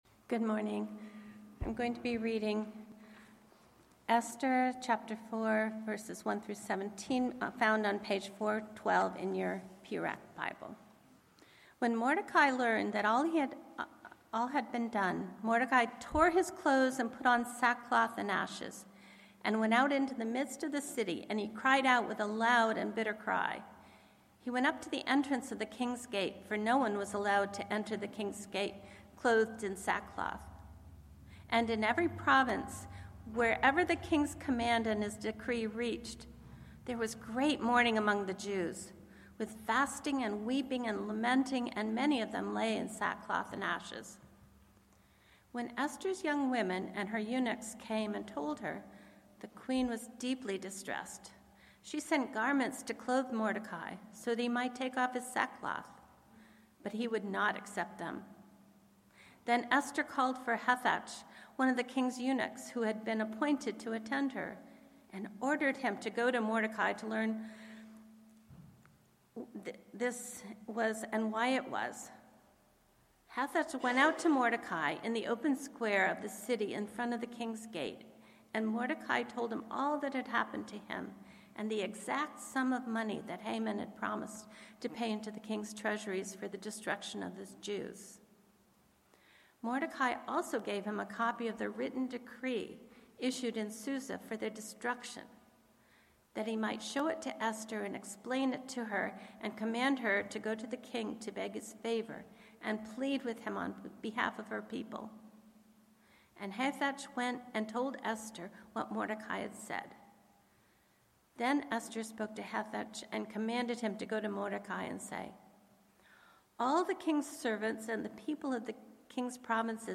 Passage: Esther 4:1-17 Sermon